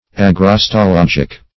Search Result for " agrostologic" : The Collaborative International Dictionary of English v.0.48: Agrostologic \A*gros`to*log"ic\, Agrostological \A*gros`to*log"ic*al\, a. Pertaining to agrostology.